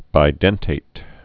(bī-dĕntāt)